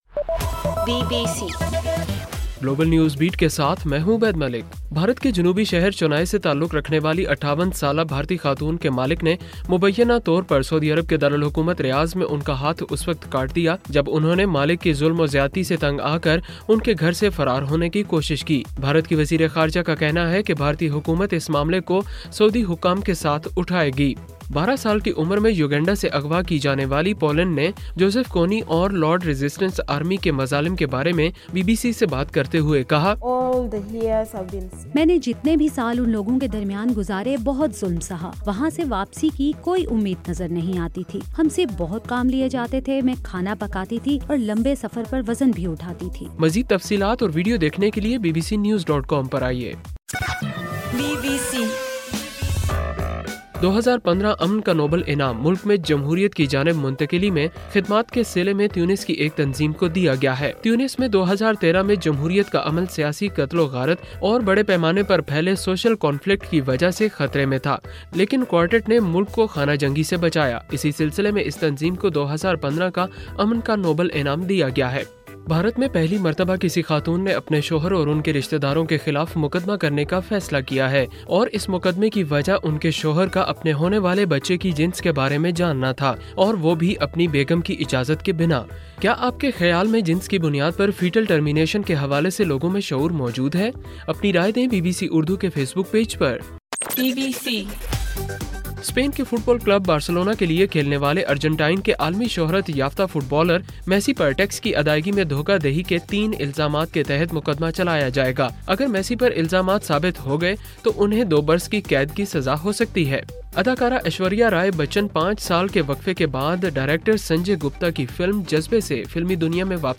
اکتوبر9: رات 8 بجے کا گلوبل نیوز بیٹ بُلیٹن